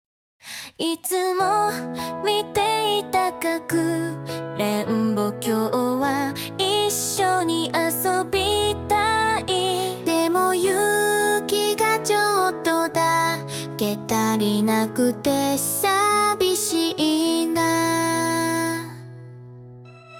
鼻歌から適当に歌詞をつけてみたやつ (Cover)